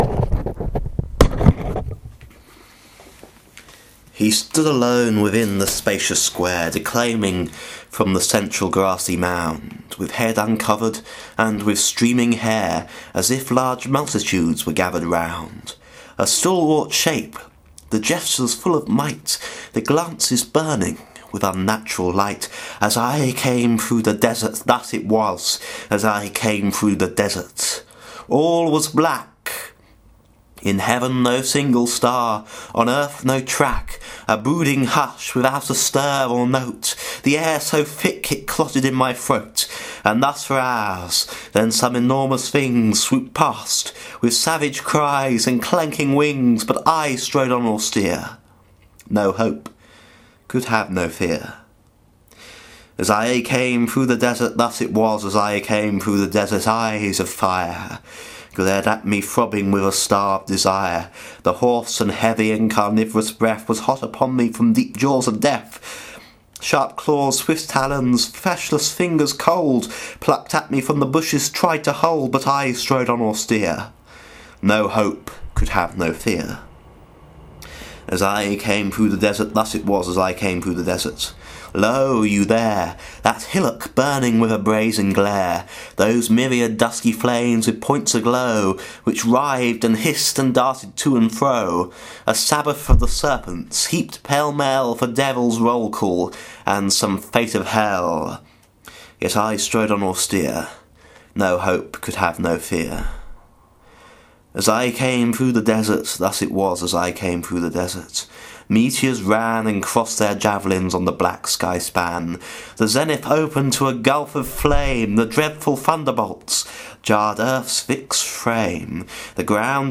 Here, for example, is a recording of Part IV of The City of Dreadful Night I made some years ago while suffering from a broken heart and an extremely bad cold: